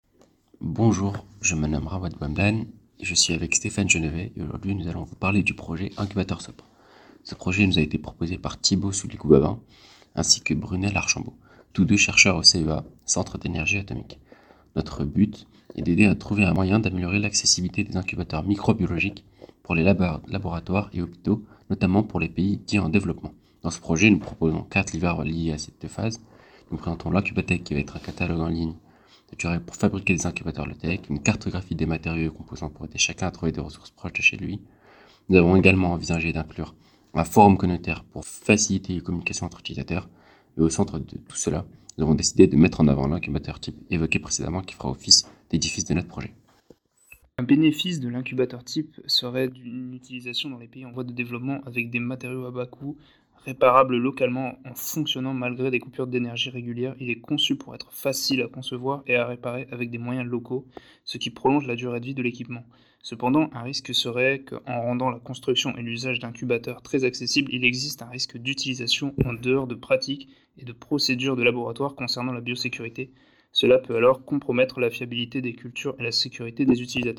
Présentation audio du projet